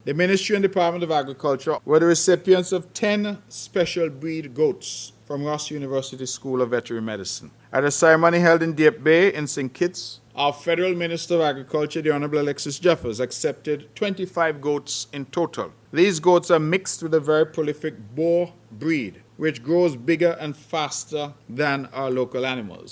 Premier Mark Brantley gave more details: